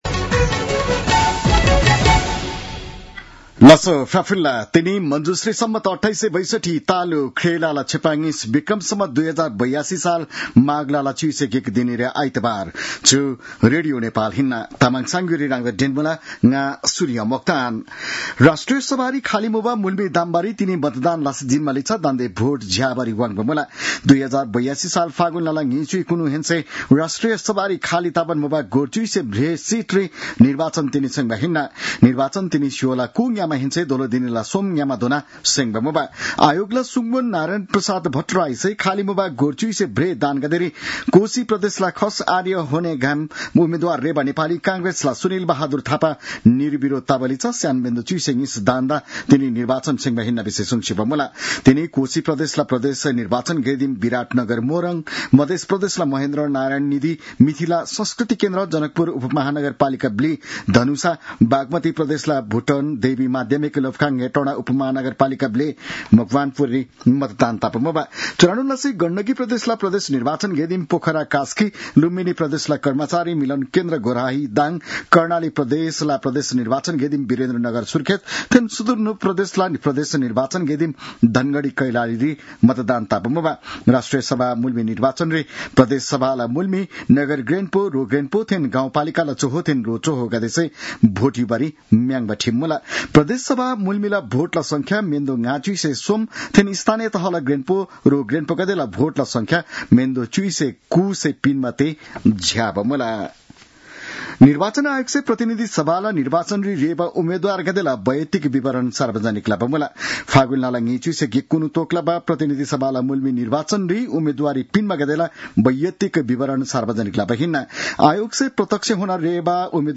तामाङ भाषाको समाचार : ११ माघ , २०८२
Tamang-news-10-11.mp3